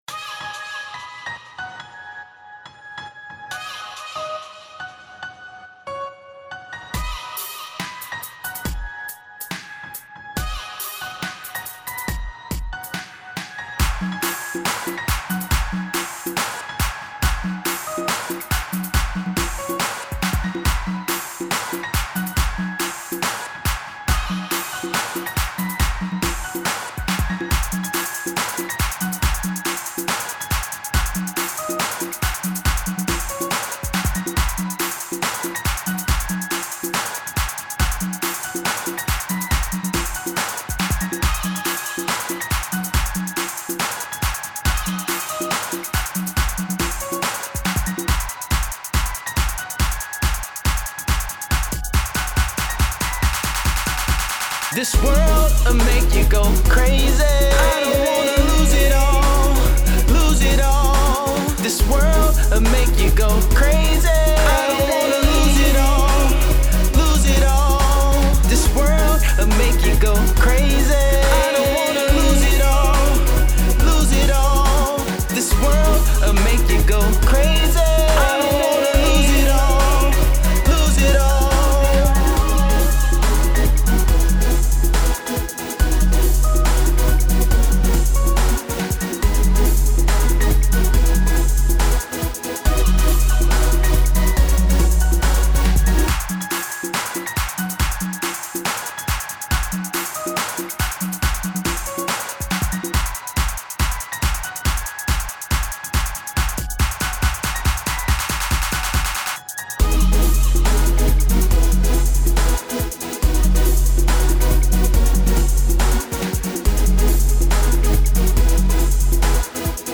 Bass, Broken Beats
Bass music